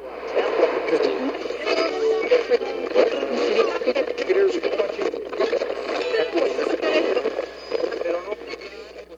FX
Radio.wav